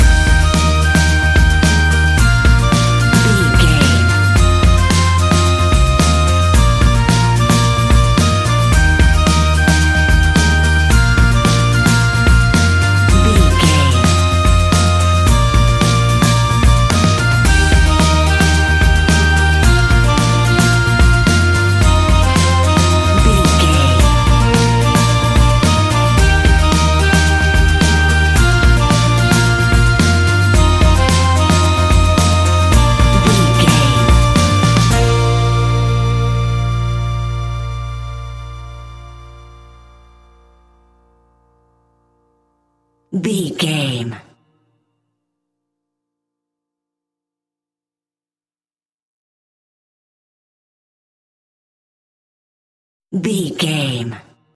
Uplifting
Ionian/Major
E♭
acoustic guitar
mandolin
double bass
accordion